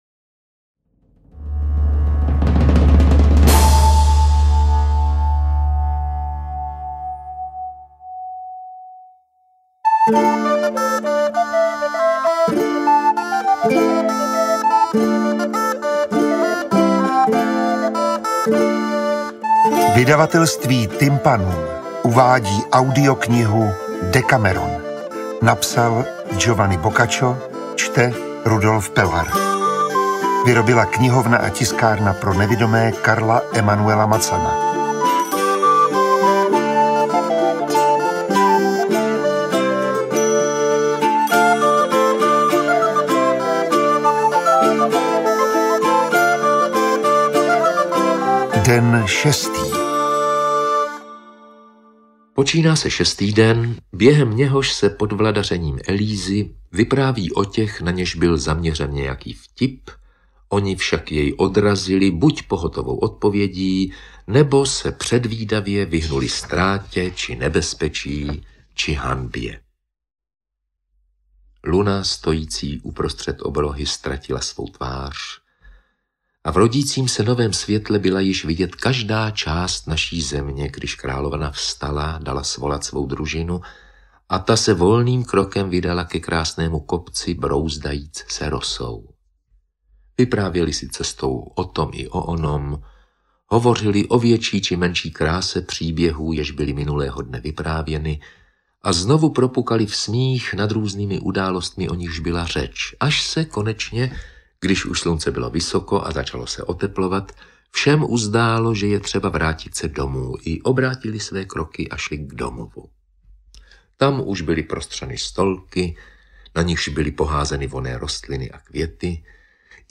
Interpret:  Rudolf Pellar